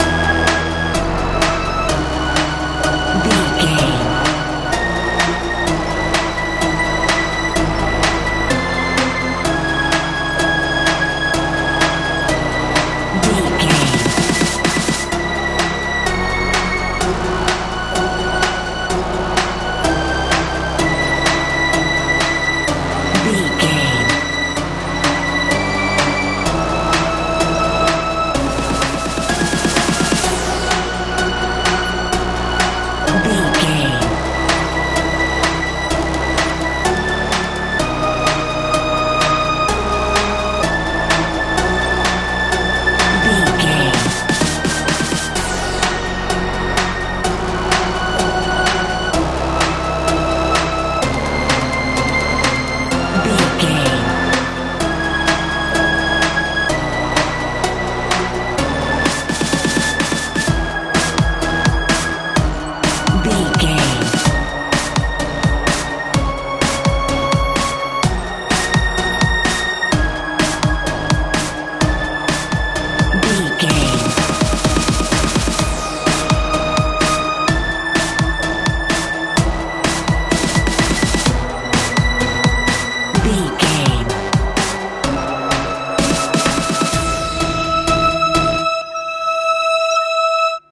techno
Aeolian/Minor
magical
mystical
bass guitar
synthesiser
drums
80s
90s